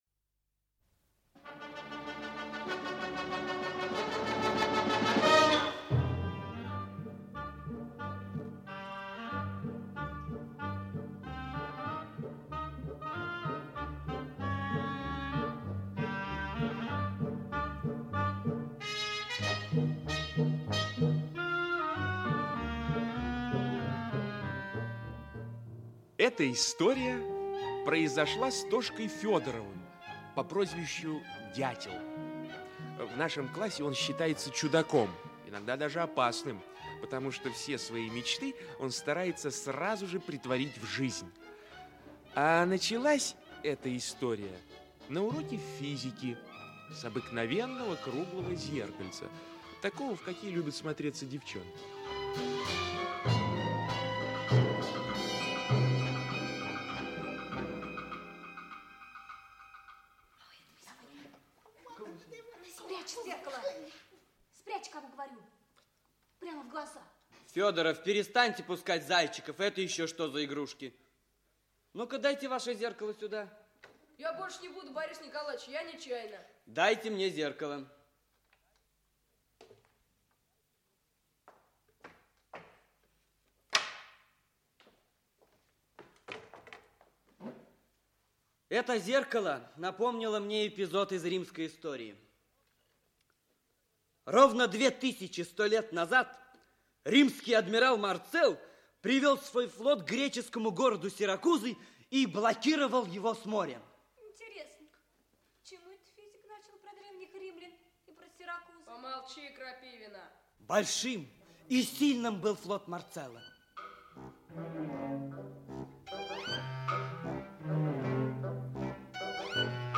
Аудиокнига ФАКС | Библиотека аудиокниг
Aудиокнига ФАКС Автор Николай Внуков Читает аудиокнигу Актерский коллектив.